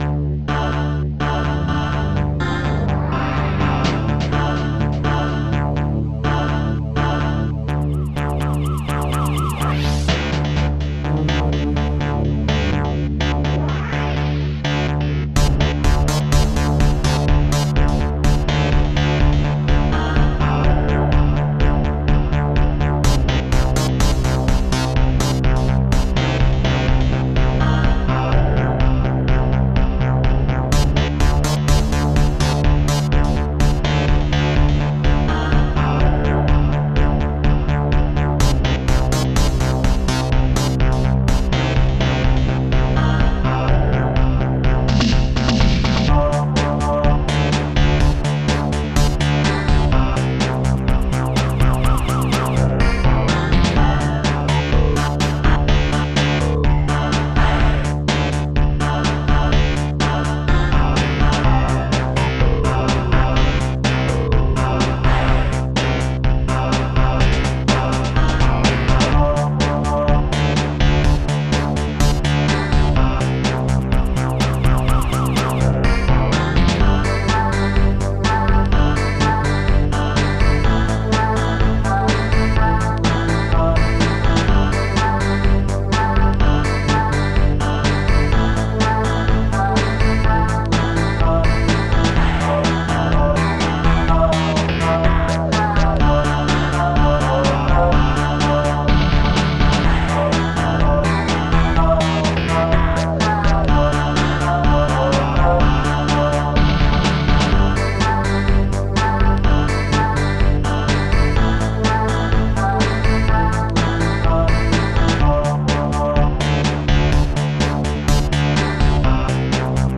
Protracker and family
st-07:Hardsynth
st-07:moog1
st-07:newstring1
st-07:sirene
st-01:BassDrum3
st-01:Strings4